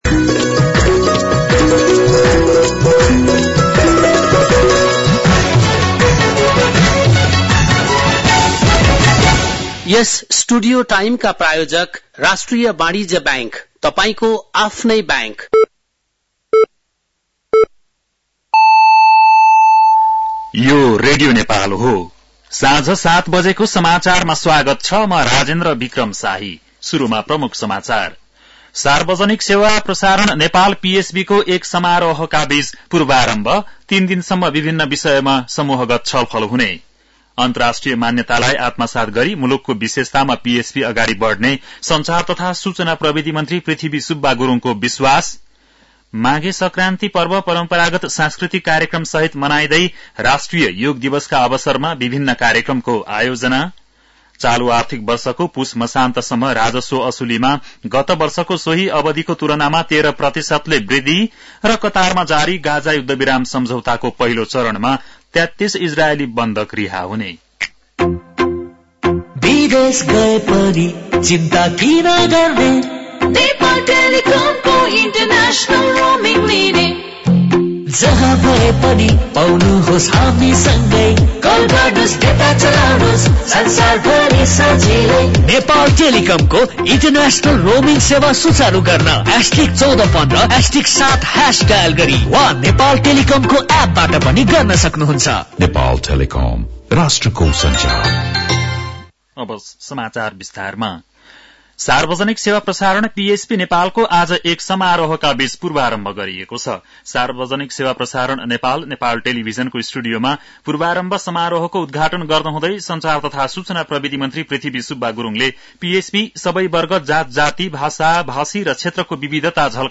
बेलुकी ७ बजेको नेपाली समाचार : २ माघ , २०८१
7-PM-Nepali-News-.mp3